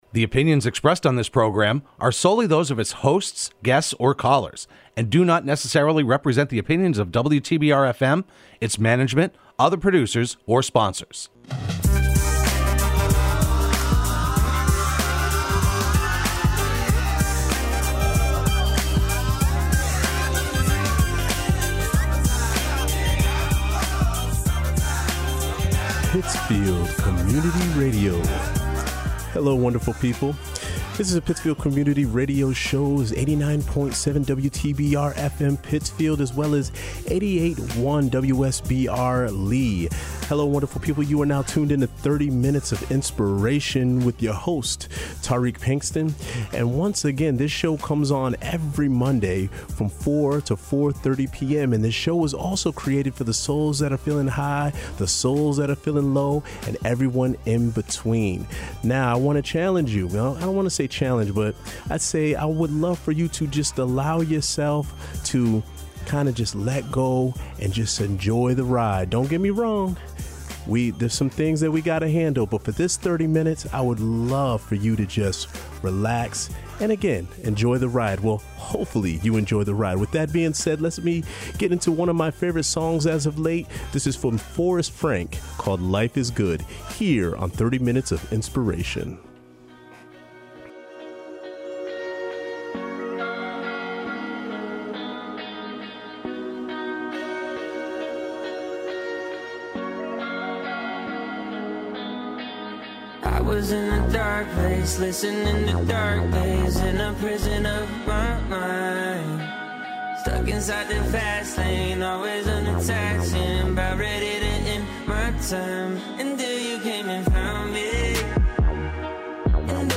broadcast live every Monday afternoon at 4pm on WTBR.